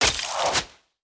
Free Fantasy SFX Pack
Ice Throw 1.wav